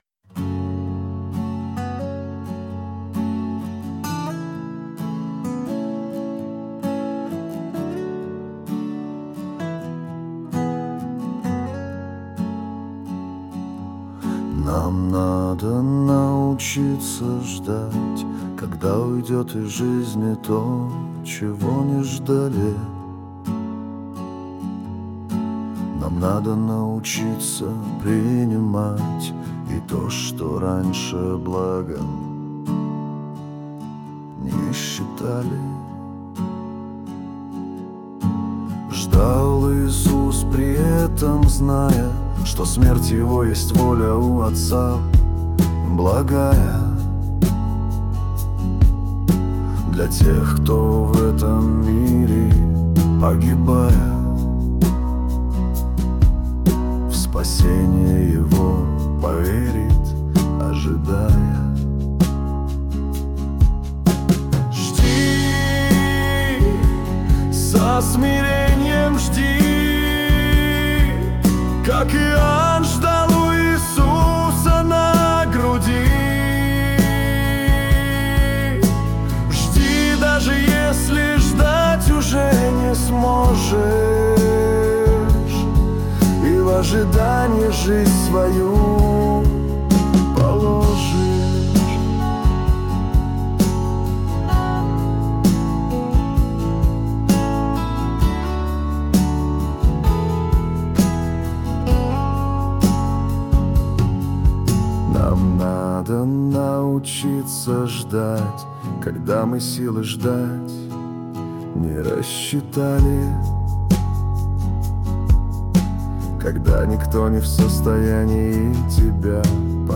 песня ai
245 просмотров 1181 прослушиваний 80 скачиваний BPM: 65